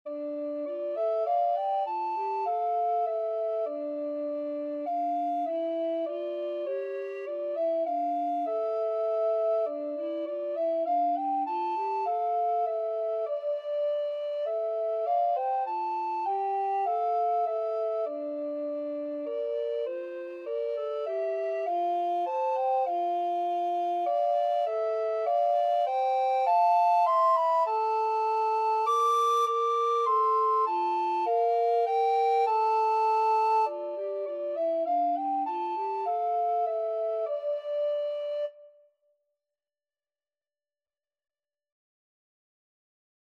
Free Sheet music for Recorder Duet
Alto RecorderTenor Recorder
D minor (Sounding Pitch) (View more D minor Music for Recorder Duet )
Moderato
Classical (View more Classical Recorder Duet Music)